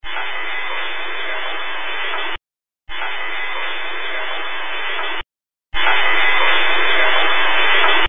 Audio recorded while walking through the basement of Knight Hall on the campus of the University of Wyoming. You can hear the steam running through the pipes in all of these clips. They were recorded with an RCA Digital Recorder Model # RP5011A.
It is looped three times and the final audio sample has the volume boosted. This clip and all others captured today have gone through heavy filtering.
It sounds like a young female.